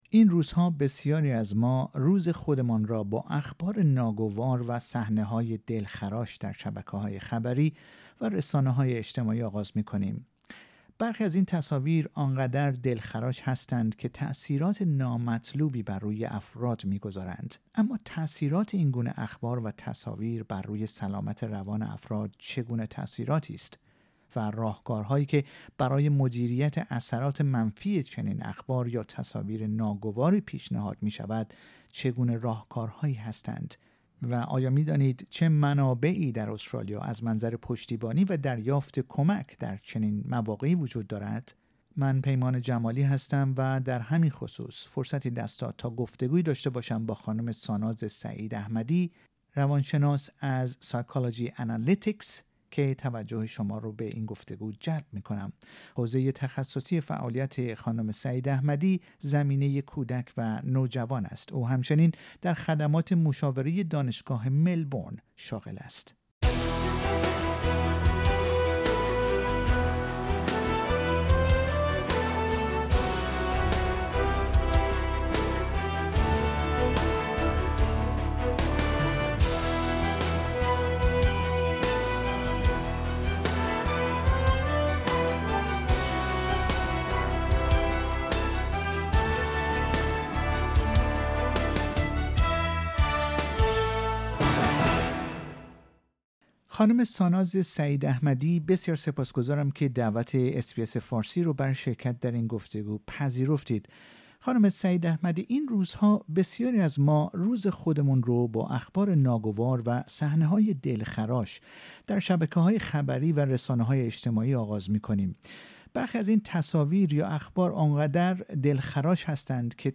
روان شناس در گفتگو با رادیو اس بی اس فارسی به این پرسش ها پاسخ می دهد.